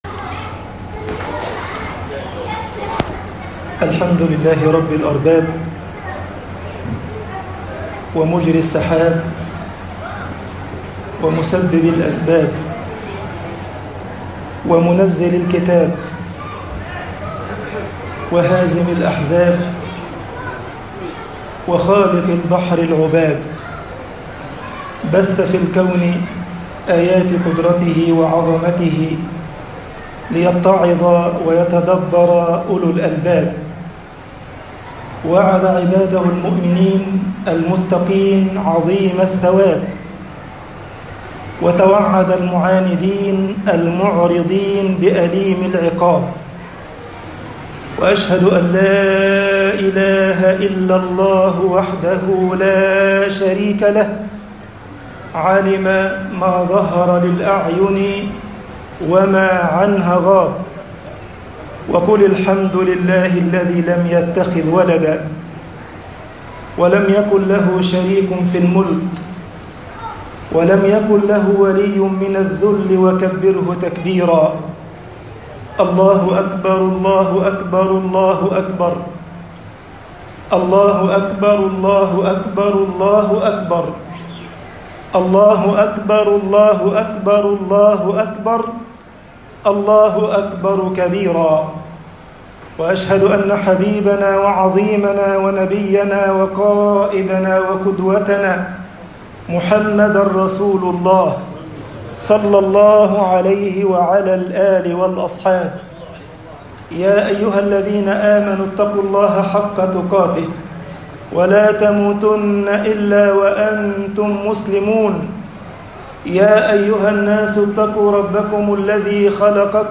خطبة عيد الأضحى 1442هـ (قصَّةُ الْفِدَاءِ)
Khutbatu eid-il-adh-ha 1442h - Qisatu-l-fidaa.mp3